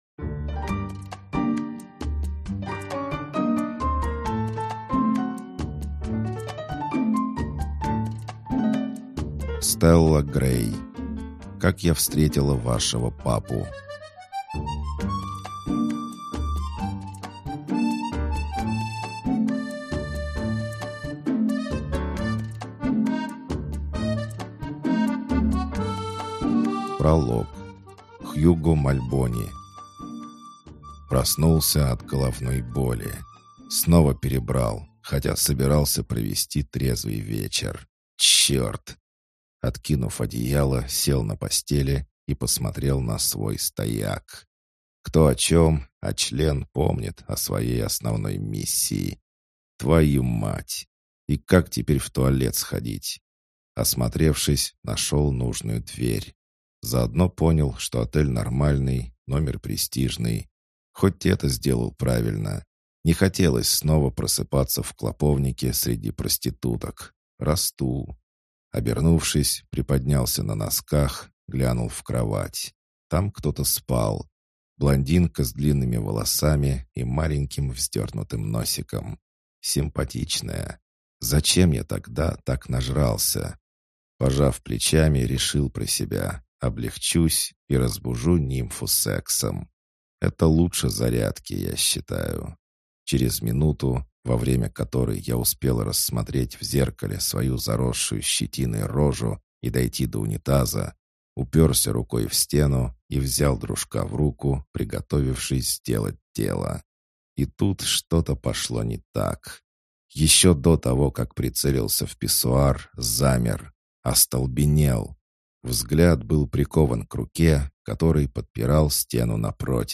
Аудиокнига Как я встретила вашего папу | Библиотека аудиокниг